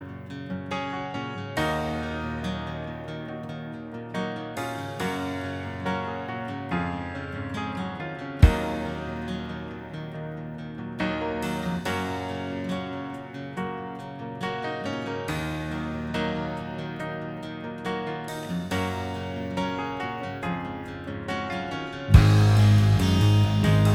Minus Guitars Rock 7:23 Buy £1.50